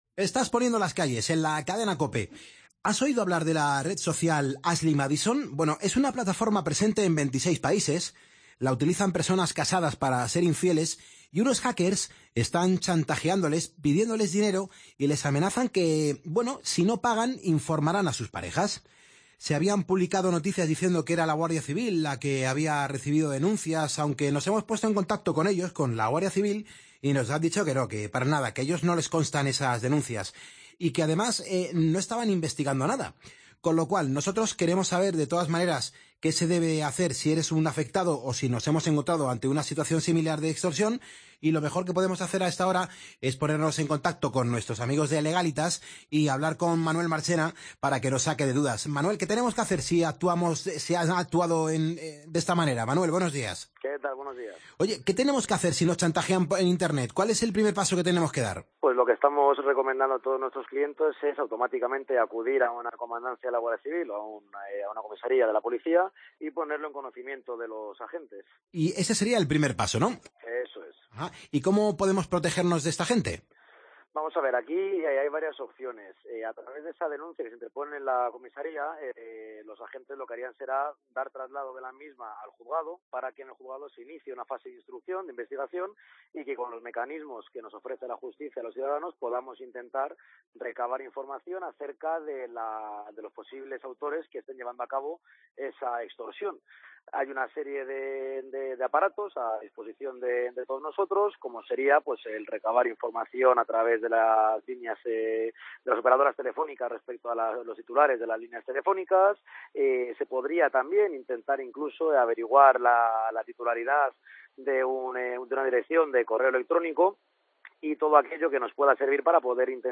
Entrevistas en Poniendo las calles